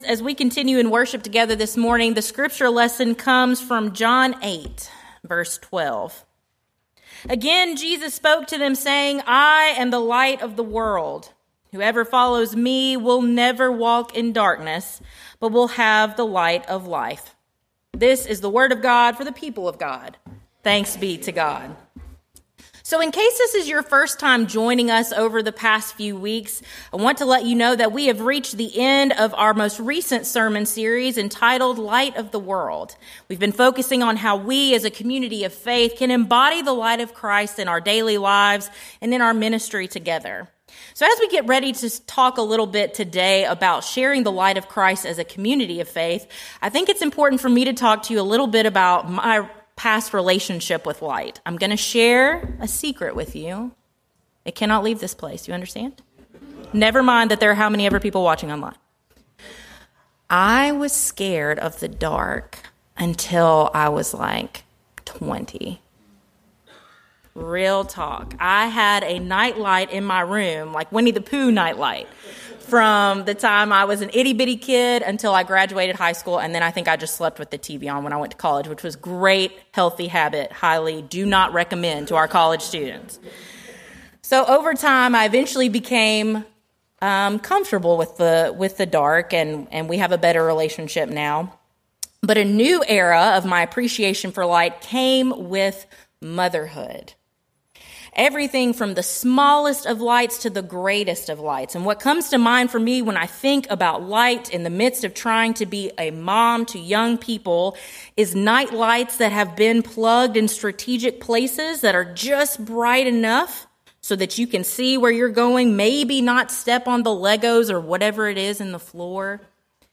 In our final sermon of the series, we will tie together the themes of individual and collective witness.